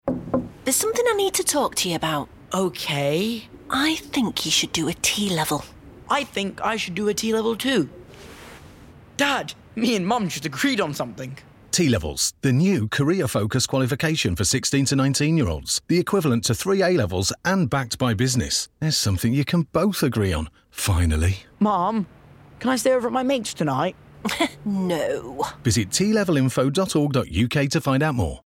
14-16 | Bright & Clear
Voice reel